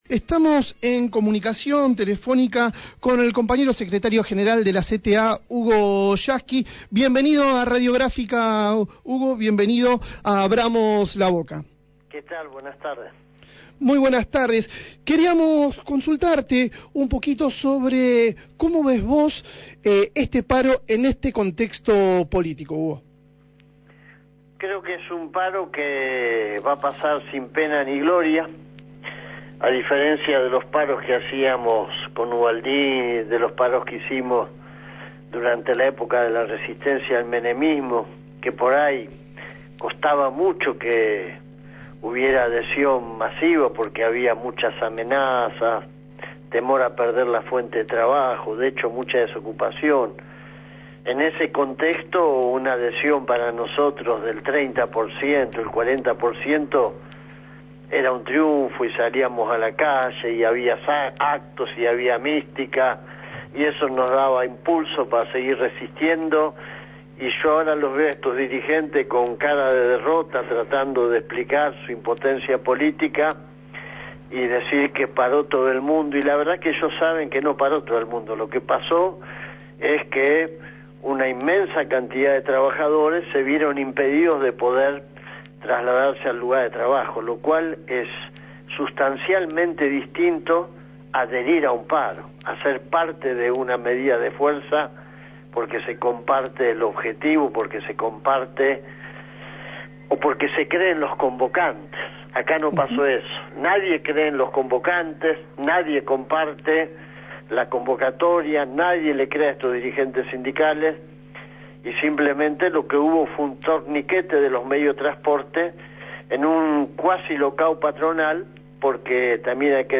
Hugo Yasky, Secretario General de la CTA, fue entrevistado en Abramos la Boca, a raíz del paro organizado por gremios opositores al gobierno nacional.